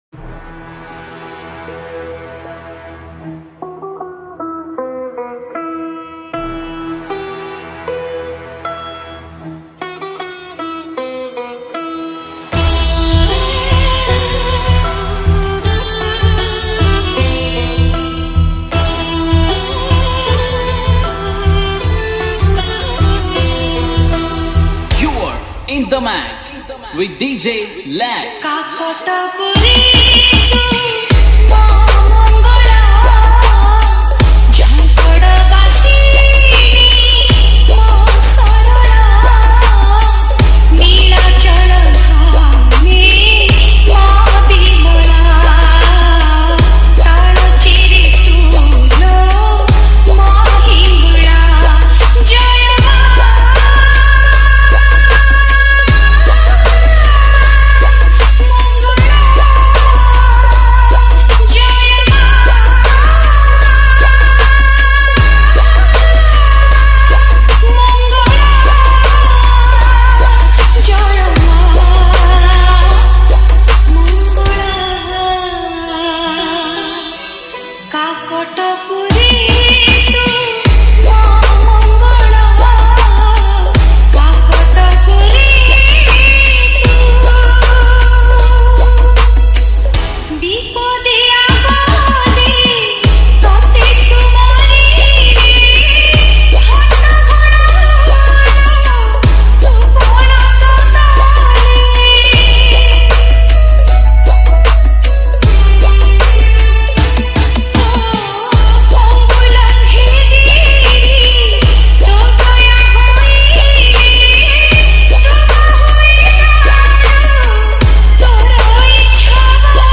Bhajan Dj Song Collection 2025